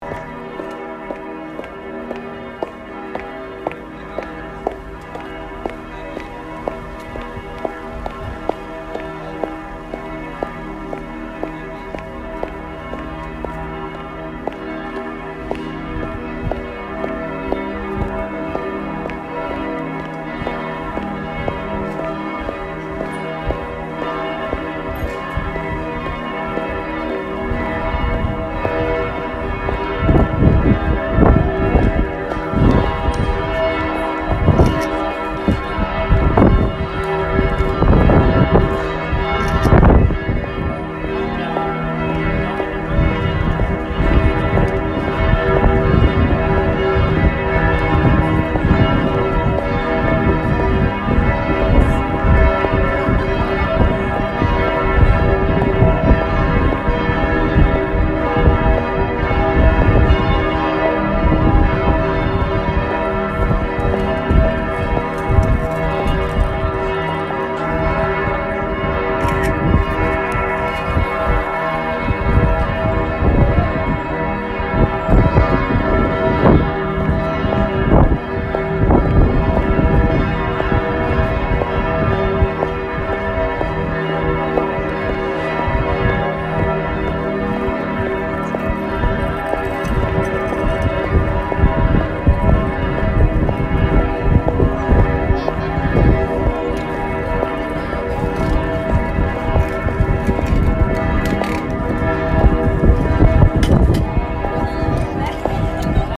geh stephansdom high heels photo click wien
geh_stephansdom_high_heels_click_wien.mp3